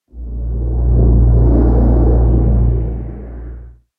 cave9.mp3